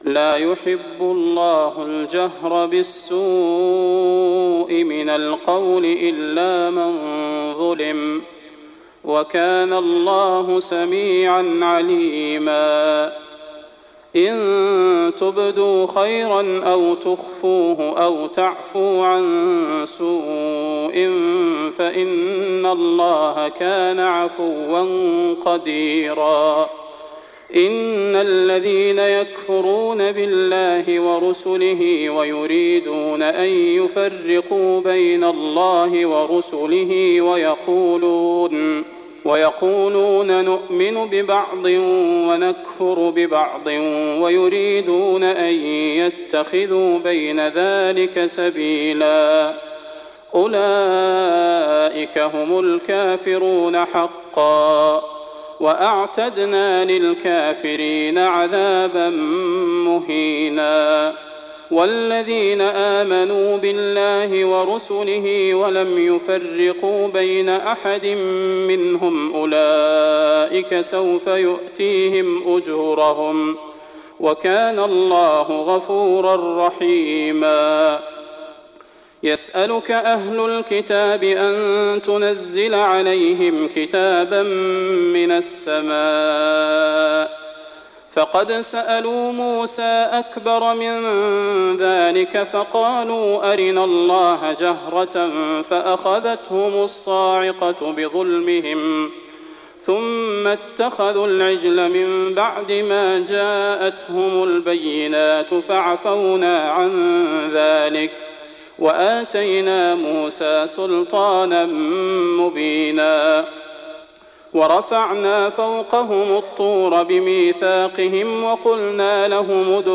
النساء 148-176 البدير تهجد 1419